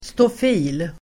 Uttal: [stof'i:l]